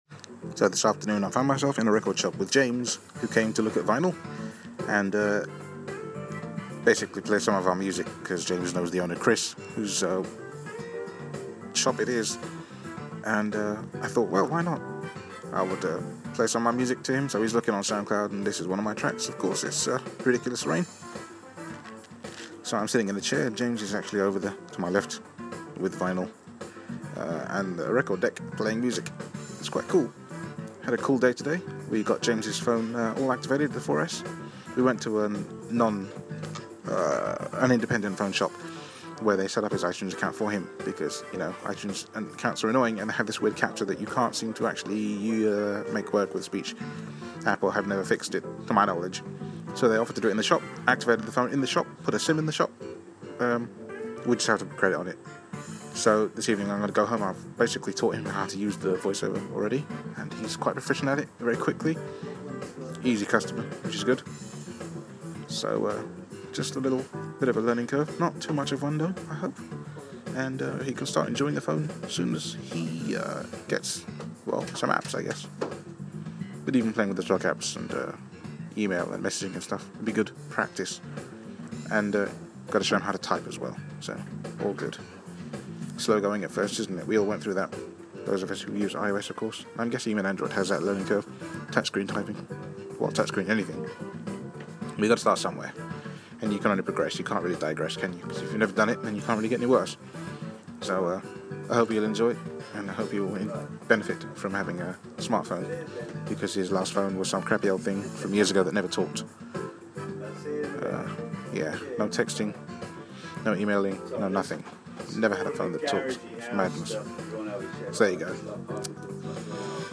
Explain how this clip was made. hanging out in a record shop... Yes there's proper vinal here!